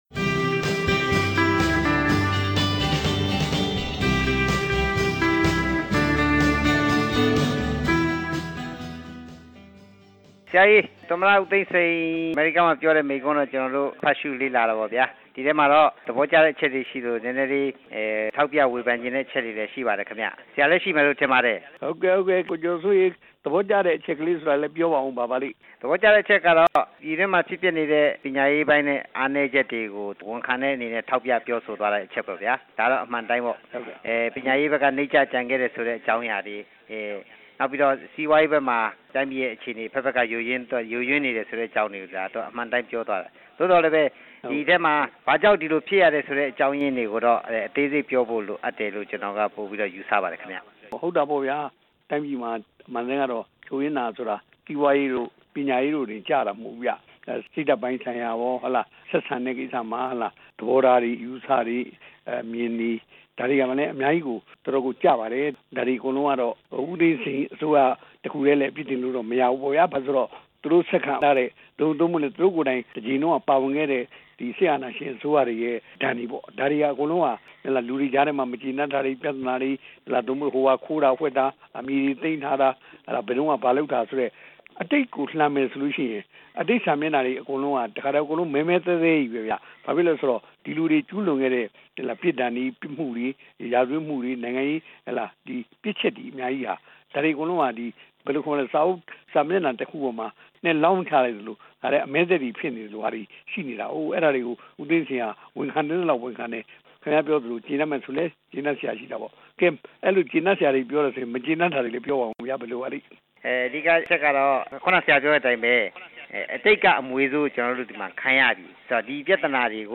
ဦးဝင်းတင်နှင့် စကားပြောခြင်း